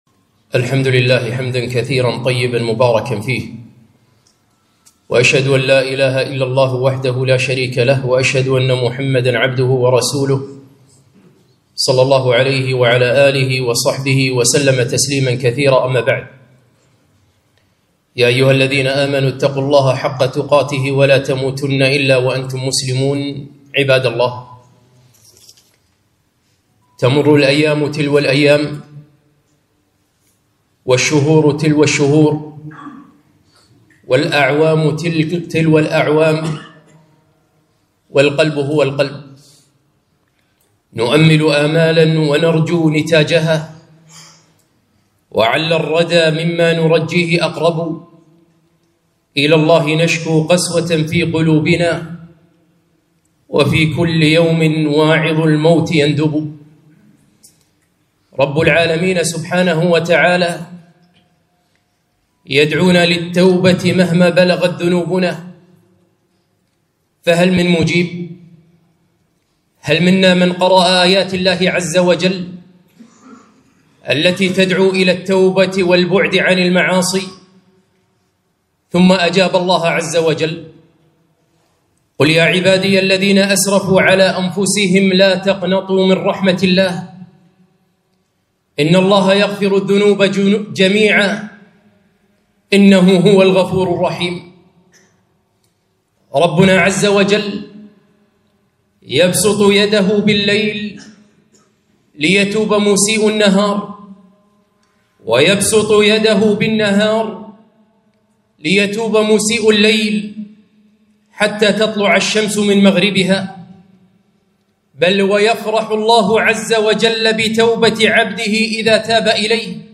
خطبة - من يمنع المذنب من التوبة